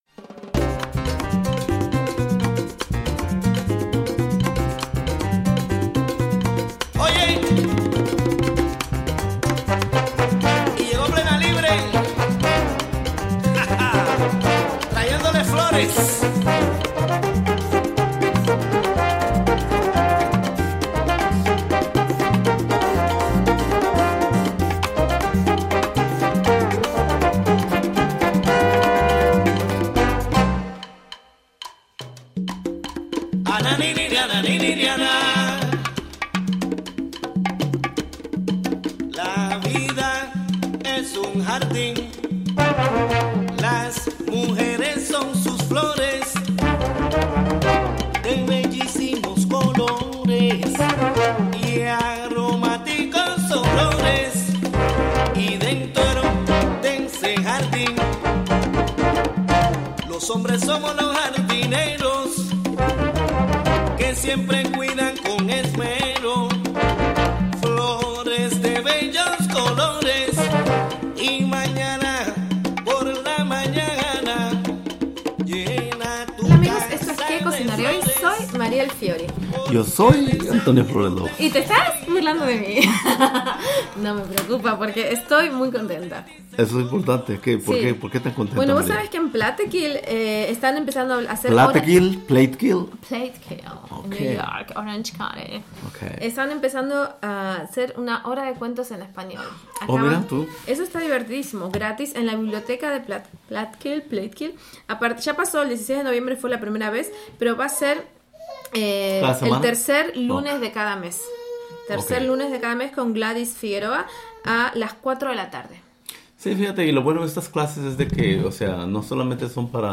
11am Weekly Spanish language radio news show hosted by...